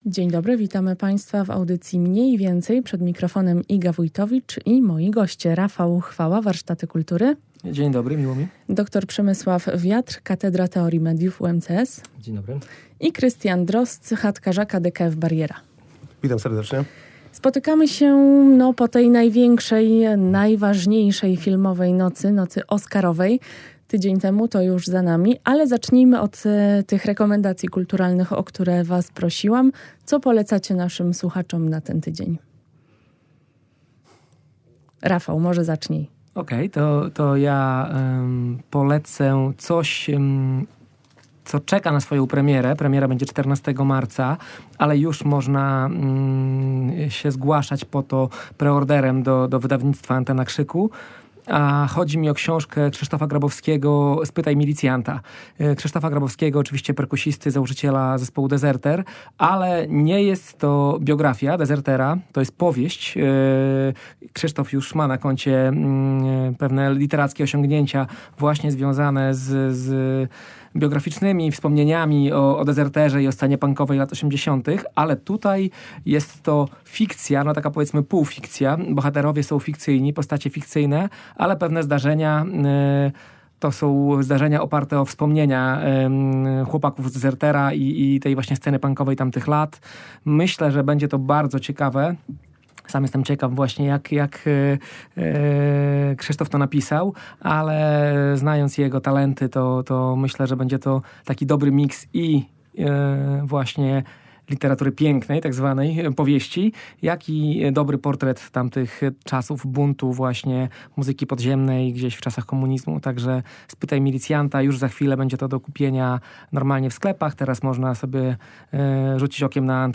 O tym porozmawiam z moimi gośćmi, a są nimi: